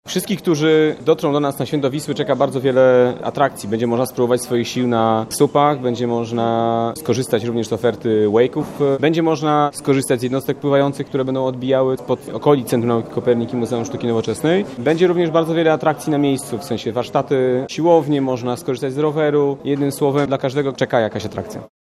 – Każdy, kto przyjdzie nad Wisłę, znajdzie coś dla siebie – mówi wiceprezydent Warszawy Michał Olszewski.